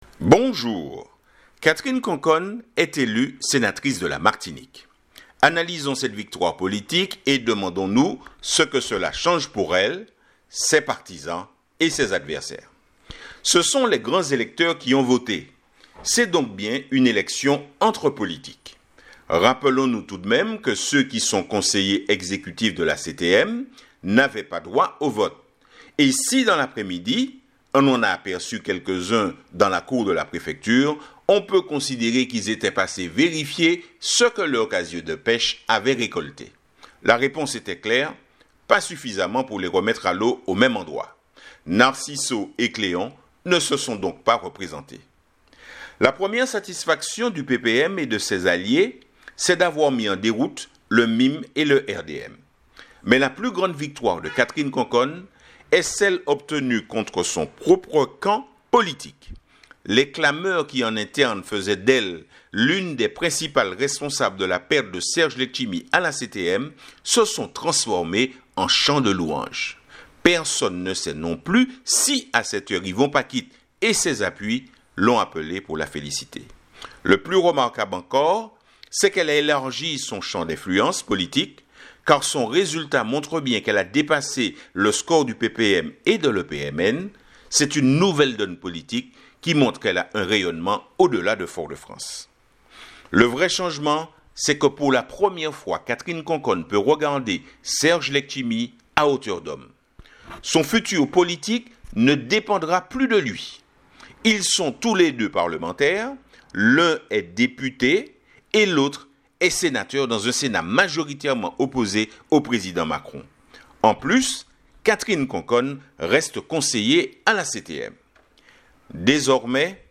Editorial du jour / Catherine CONCONNE est élue Sénatrice de la MARTINIQUE. Analysons cette victoire politique